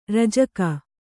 ♪ rajaka